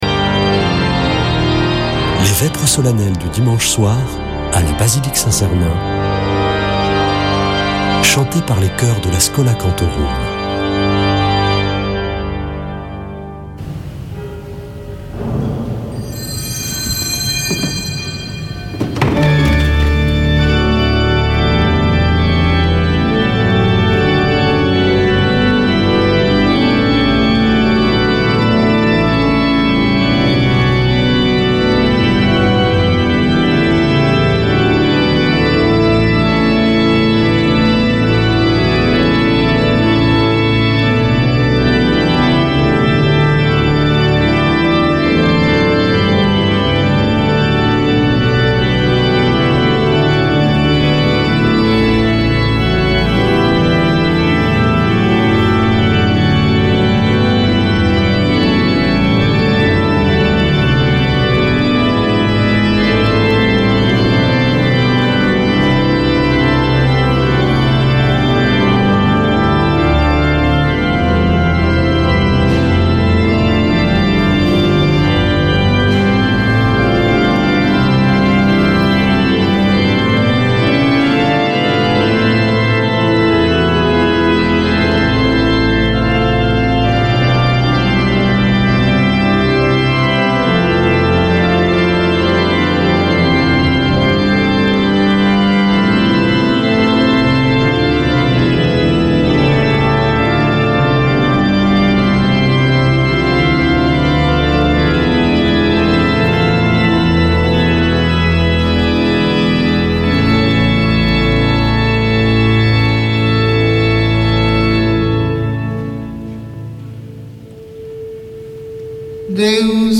Vêpres de Saint Sernin du 10 sept.
Une émission présentée par Schola Saint Sernin Chanteurs